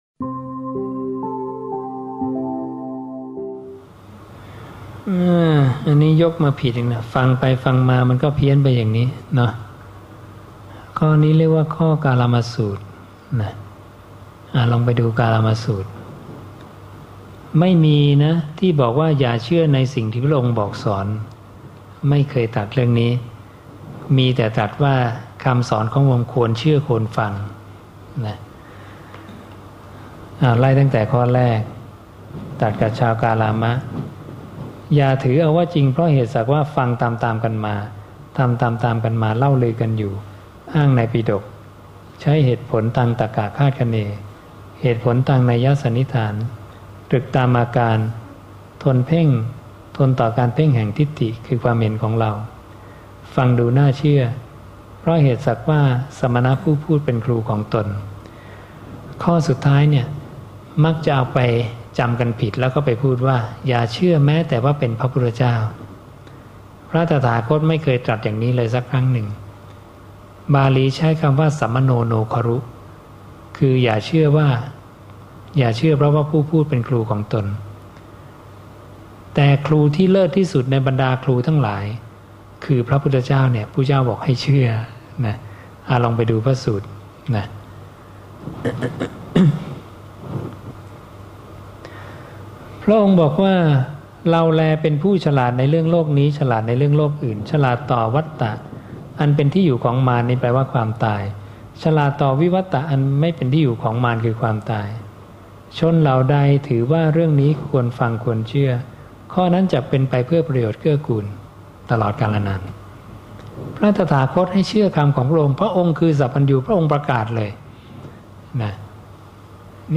บางส่วนจากการแสดงธรรม ณ ยุวพุทธธิกสมาคม 8 ส.ค. 55